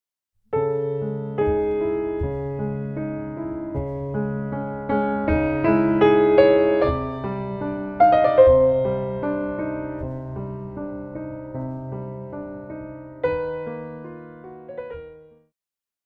古典,流行
小提琴
钢琴
演奏曲
世界音乐
仅伴奏
没有节拍器